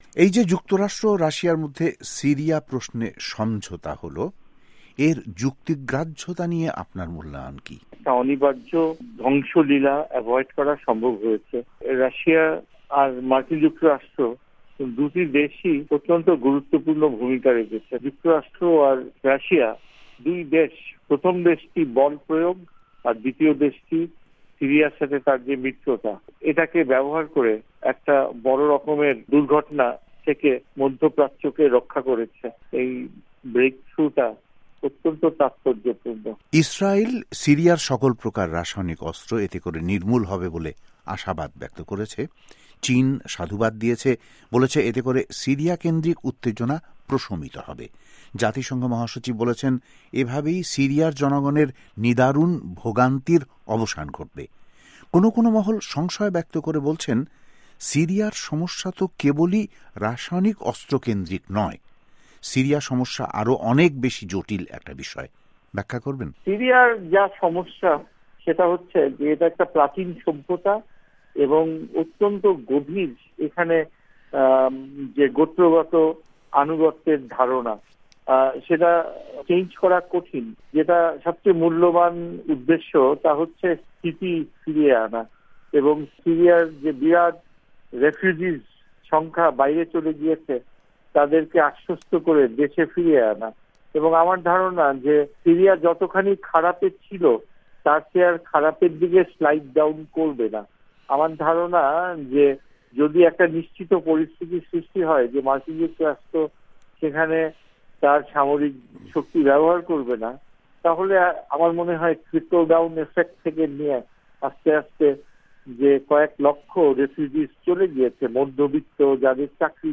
আন্তর্জাতিক টেলি কনফারেন্স লাইনে ওয়াশিংটন স্টুডিও থেকে কথা বলেন